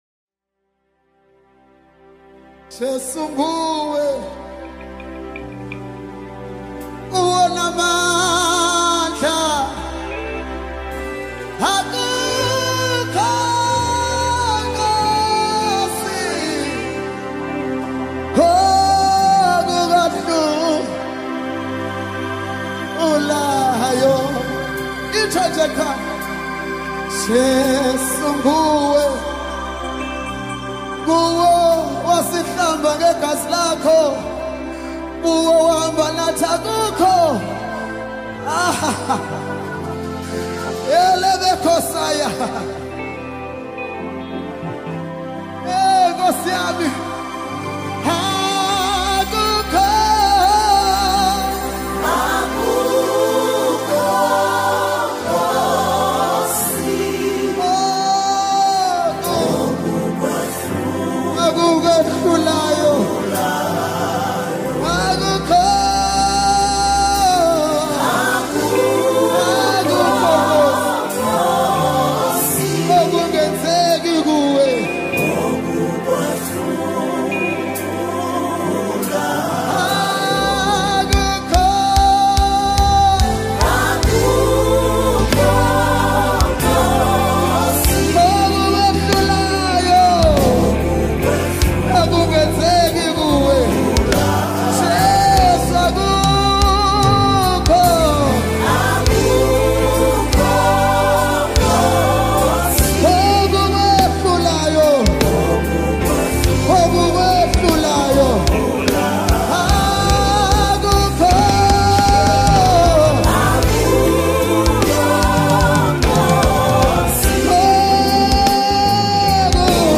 This refreshing tune is just for your soul.